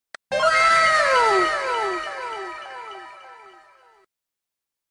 wow sound effect sound effects free download